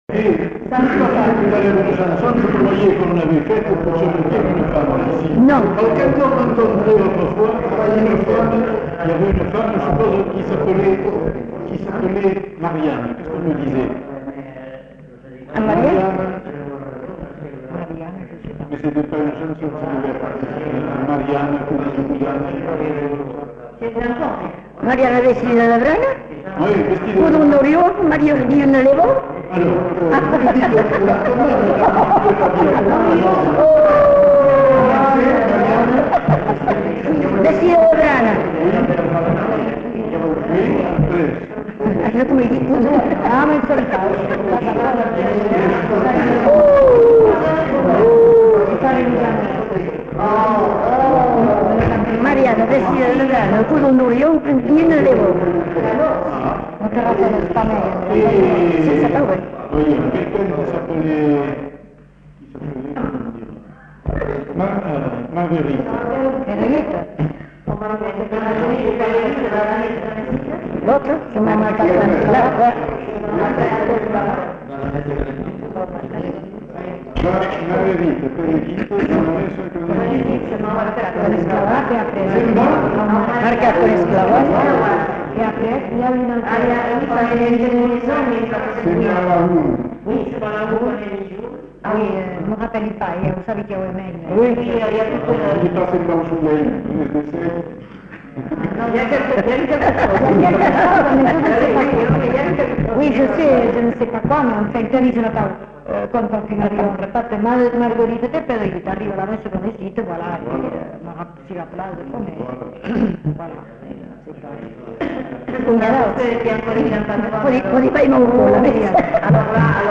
Aire culturelle : Bazadais
Lieu : Cazalis
Genre : forme brève
Effectif : 1
Type de voix : voix de femme
Production du son : récité
Classification : formulette enfantine